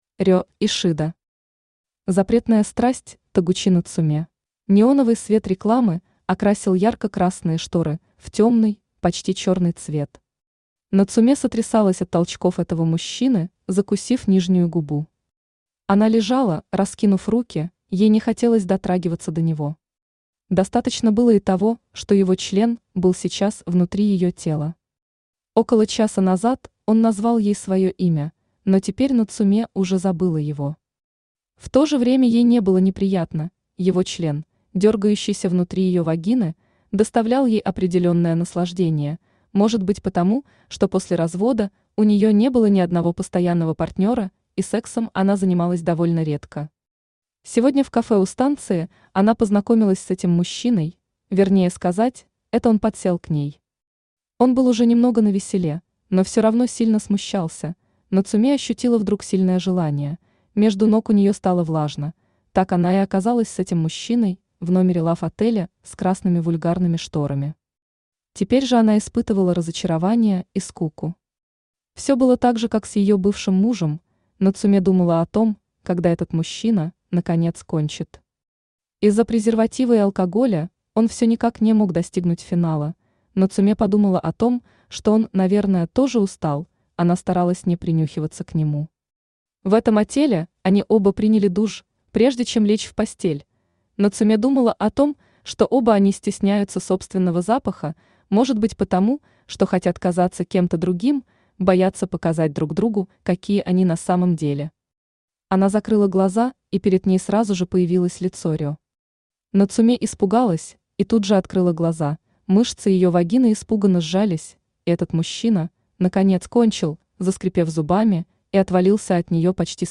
Аудиокнига Запретная страсть Тагучи Нацуме | Библиотека аудиокниг
Aудиокнига Запретная страсть Тагучи Нацуме Автор Рё Ишида Читает аудиокнигу Авточтец ЛитРес.